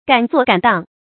敢作敢當 注音： ㄍㄢˇ ㄗㄨㄛˋ ㄍㄢˇ ㄉㄤ 讀音讀法： 意思解釋： 敢：有膽量。敢于放手行事，敢于承擔責任。